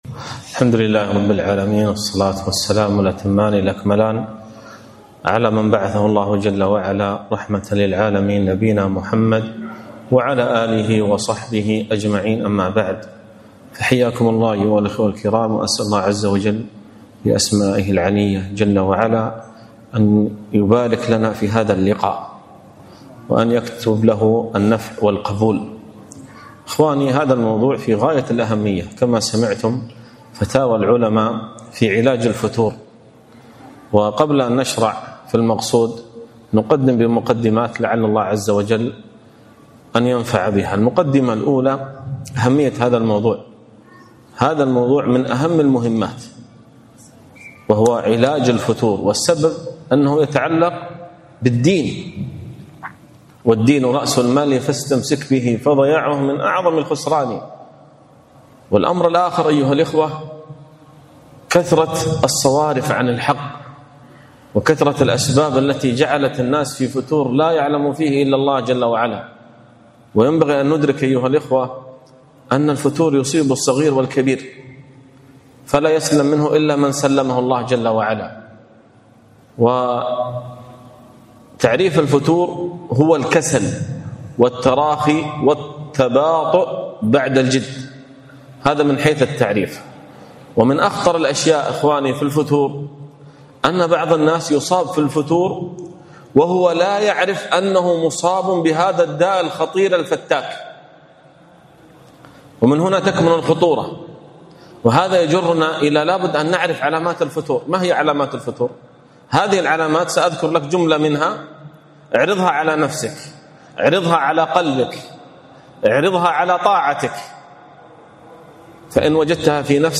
محاضرة - فتاوي العلماء في علاج الفتور